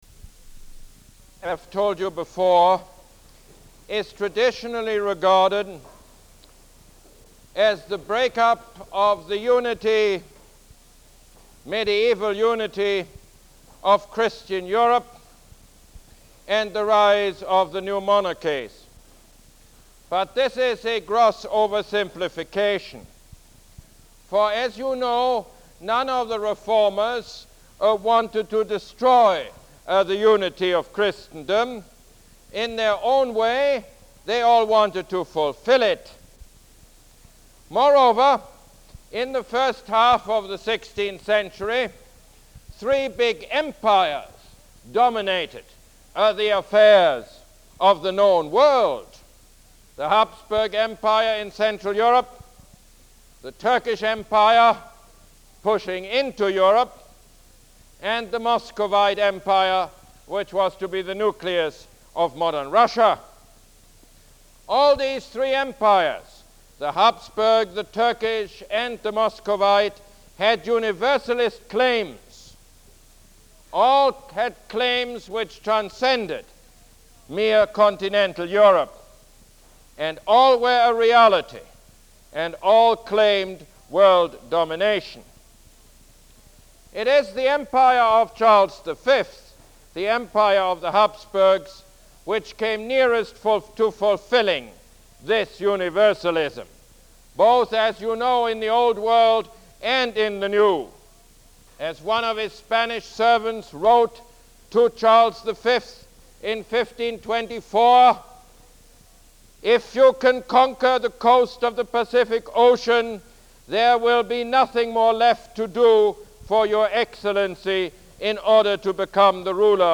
Lecture #9 - Emperor Charles the Fifth